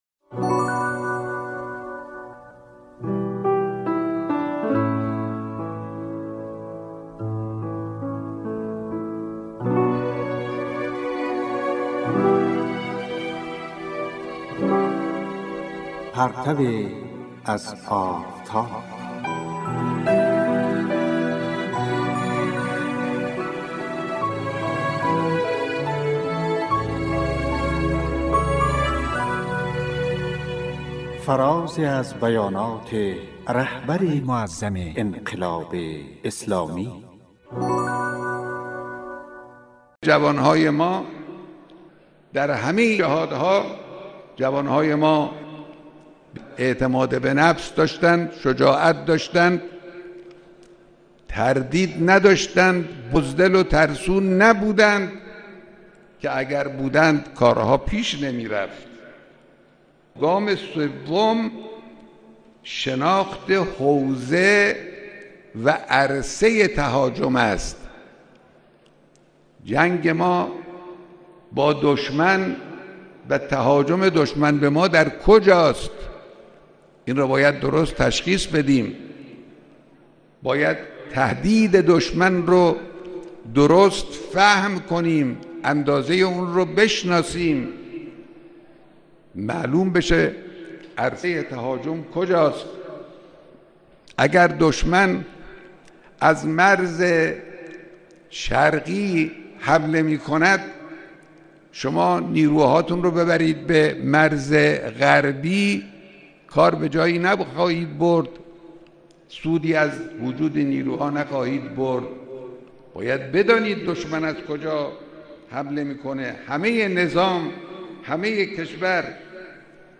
"پرتویی از آفتاب" کاری از گروه معارف رادیو تاجیکی صدای خراسان است که به گزیده ای از بیانات رهبر معظم انقلاب می پردازد.